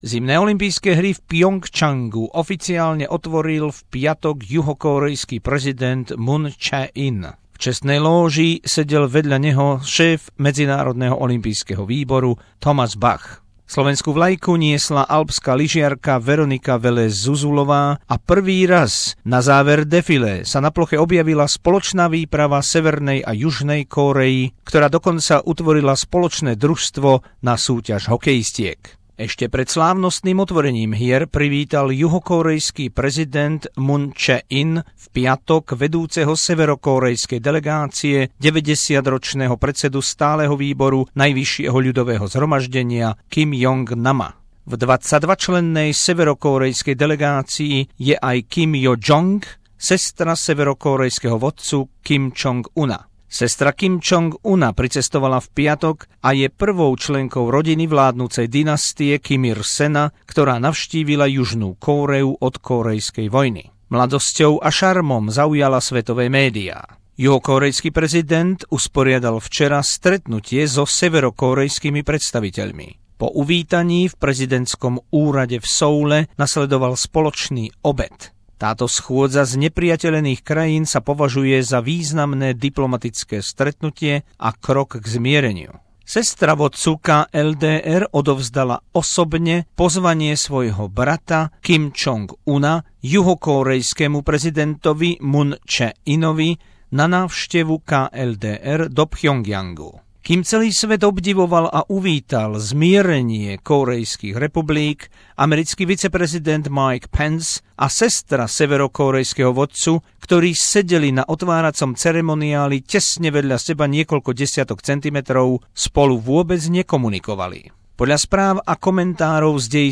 exkluzívny rozhovor priamo z dejiska hier